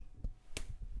Punch